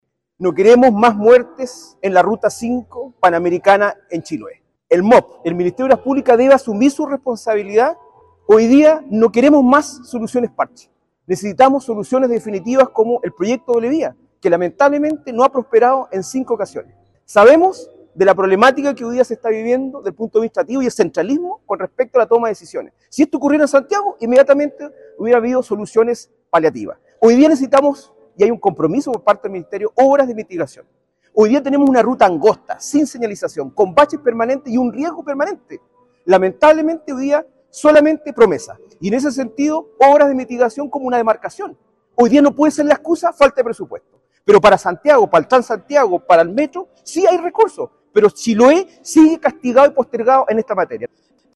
Una conferencia de prensa, ofrecieron en Puerto Montt, los consejeros regionales de la Provincia de Chiloé, para presentar sus inquietudes y su posición, con respecto al proyecto de la doble vía, que, en su primera fase, considera el tramo entre Chacao y Chonchi.